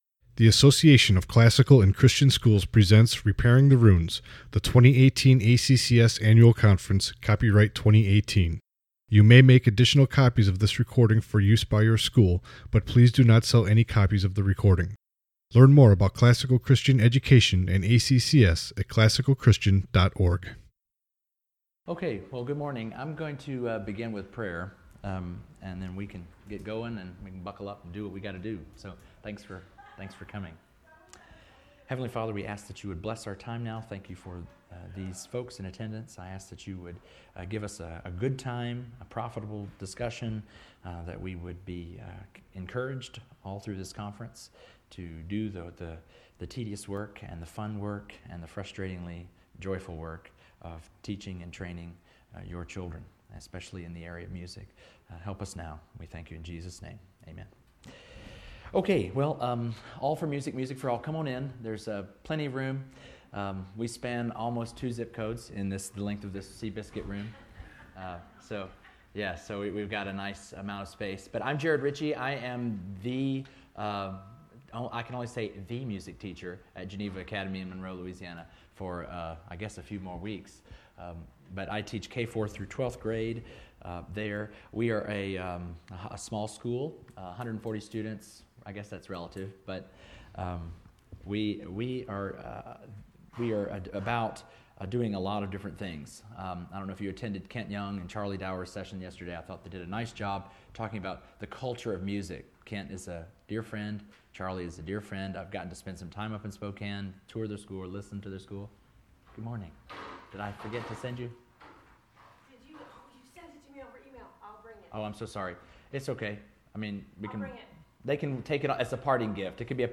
2018 Workshop Talk | 1:02:15 | All Grade Levels, Art & Music